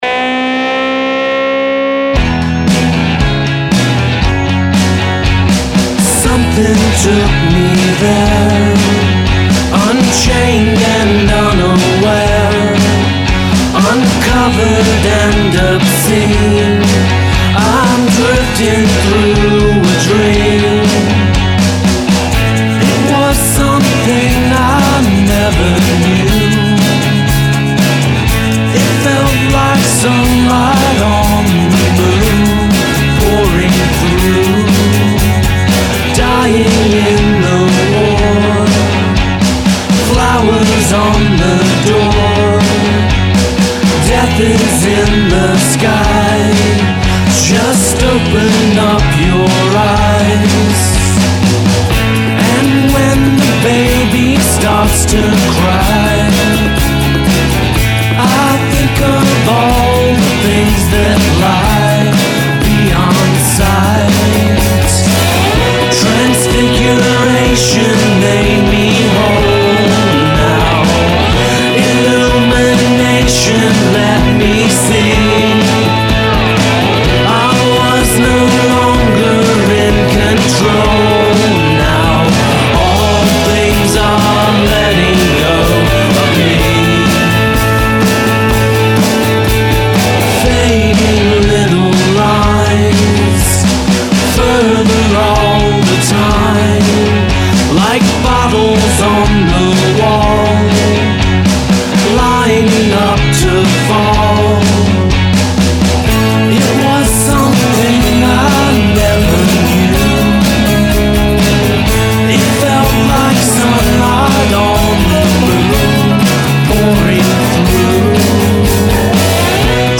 early lo-fi sound
minimalism